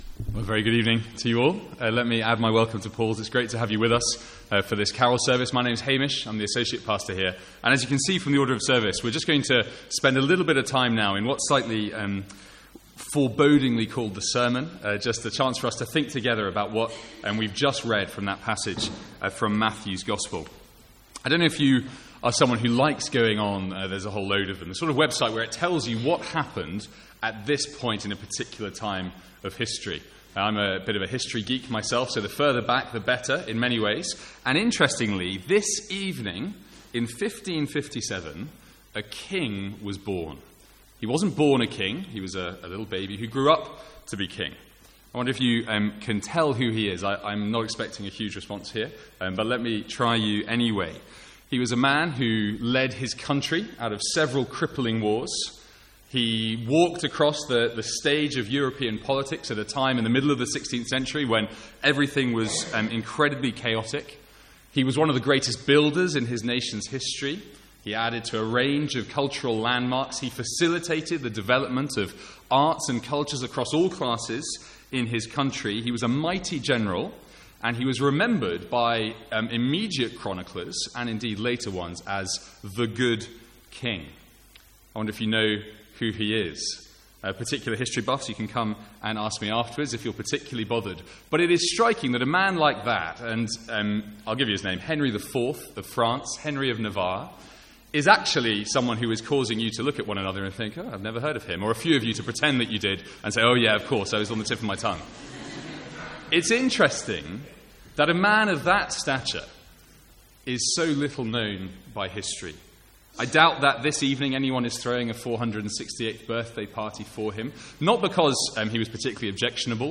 Sermons | St Andrews Free Church
From the Sunday morning Advent series 2014.